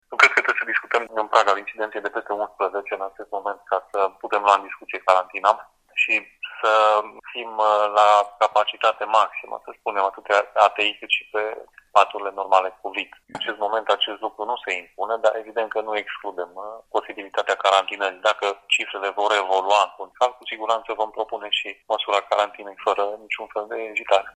În ce condiții vor intra în vigoare noi restricții a explicat pentru Europa FM prefectul Mircea Abrudan: